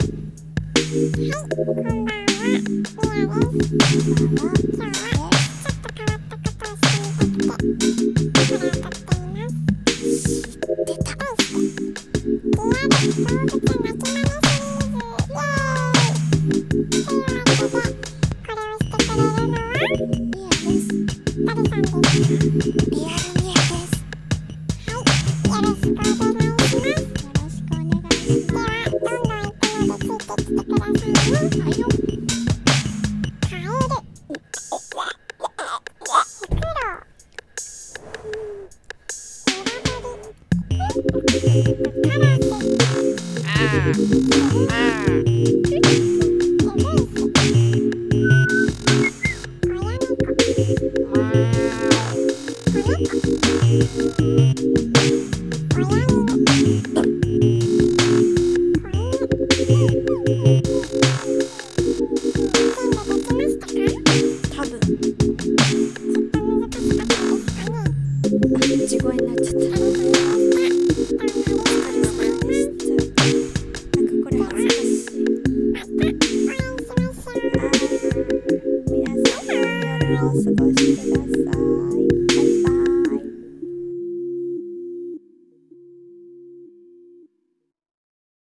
リアル動物鳴き真似シリーズ